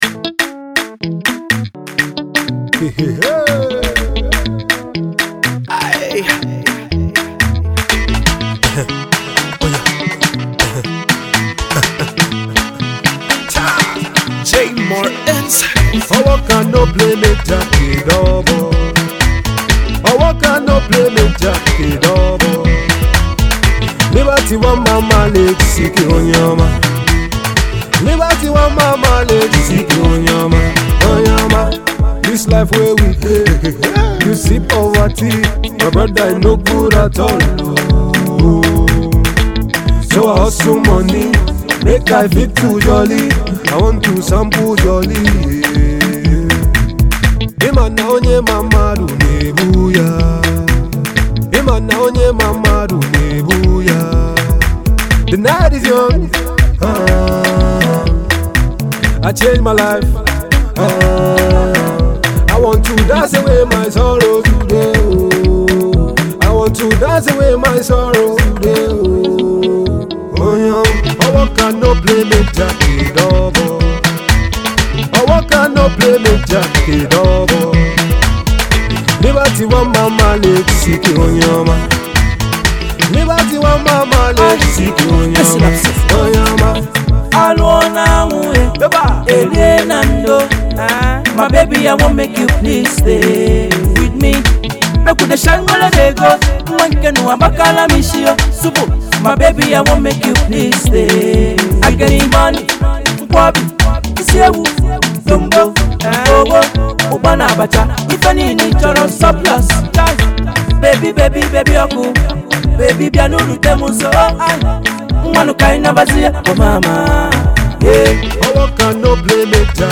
highlife upbeat song